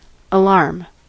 Ääntäminen
IPA : /əˈlɑːm/
IPA : /əˈlɑɹm/